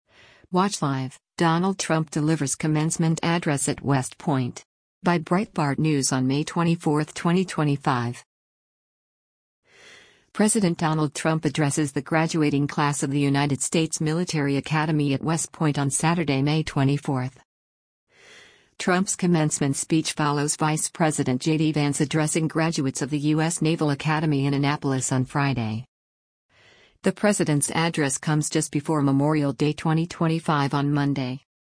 President Donald Trump addresses the graduating class of the United States Military Academy at West Point on Saturday, May 24.